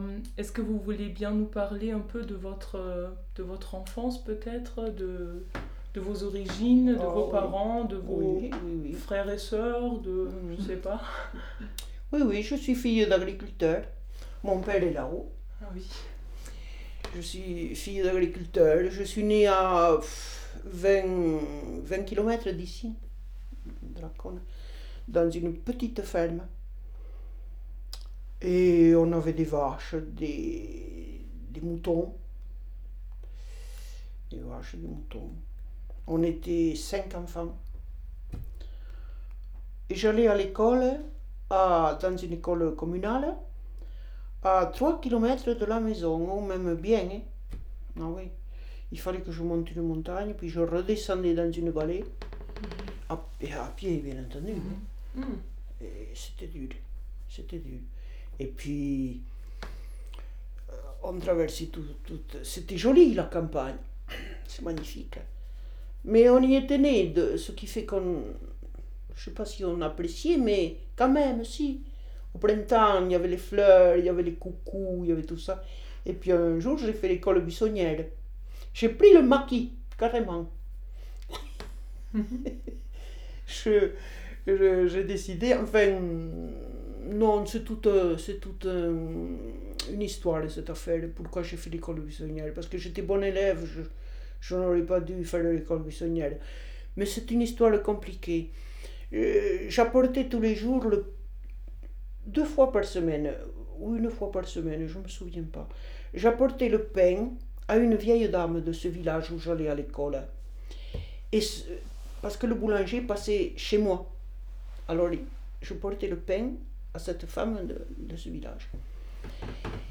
Les variétés du français parlé dans l'espace francophone. Ressources pour l'enseignement
Partie III La France hexagonale méridionale
Chapitre 3. Conversation à Lacaune (Tarn) : une leçon d’école buissonnière (5’05) (Lonnemann & Meisenburg)